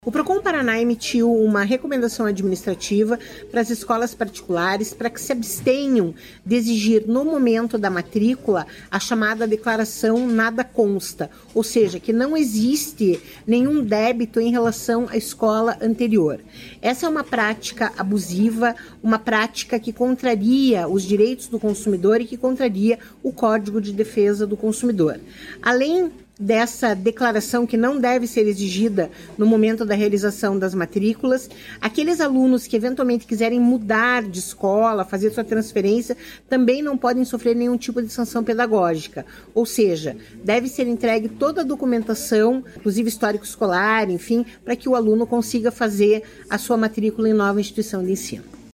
Sonora da coordenadora do Procon-PR, Claudia Silvano, sobre as recomendações para escolas não exigirem quitação de débito na matrícula